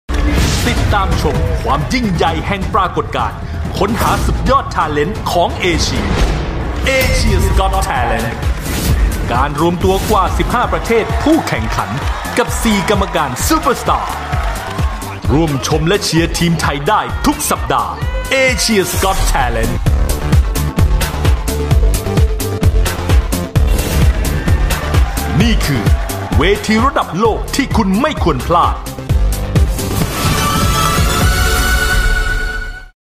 THAI MALE VOICES
male